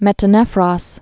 (mĕtə-nĕfrŏs)